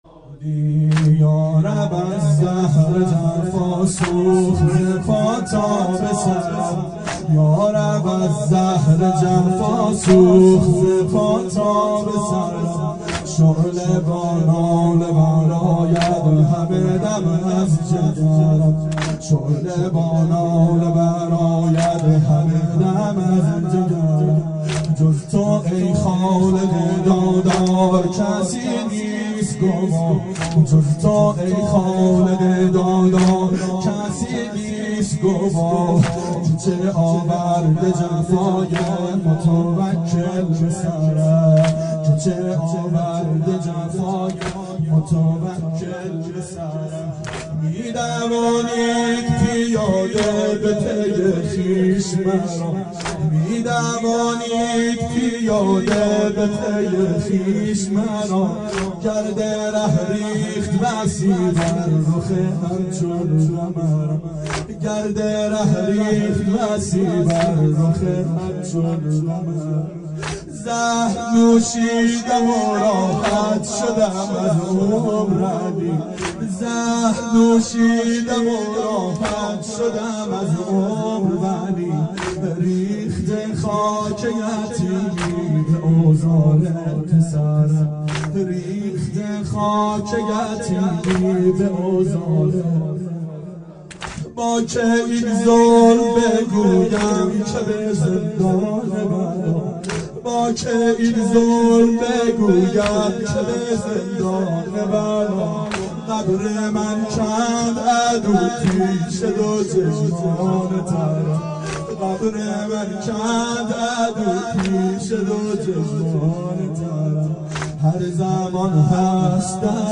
• شب شهادت امام هادی علیه السلام 92 محفل شیفتگان حضرت رقیه سلام الله علیها